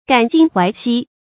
感今懷昔 注音： ㄍㄢˇ ㄐㄧㄣ ㄏㄨㄞˊ ㄒㄧ 讀音讀法： 意思解釋： 對當前的事物有所感觸而懷念過去的人、事物或景物。